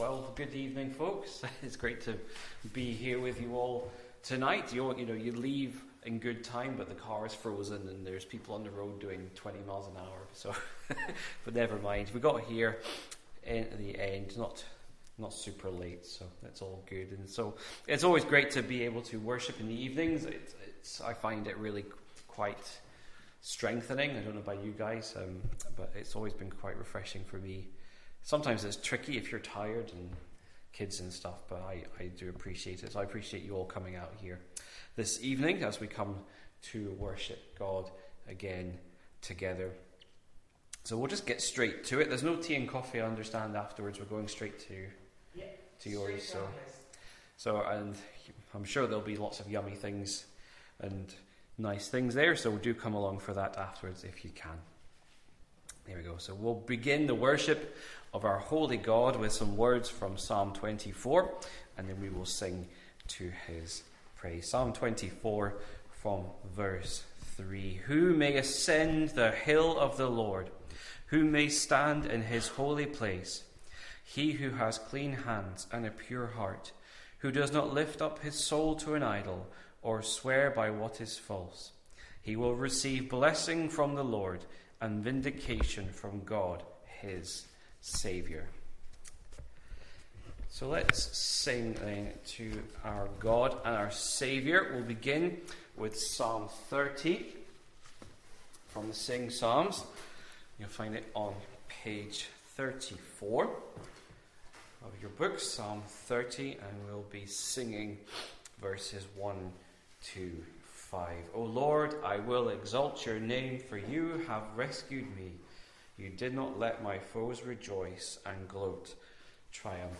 Sunday-Service-8th-6.00pm-.mp3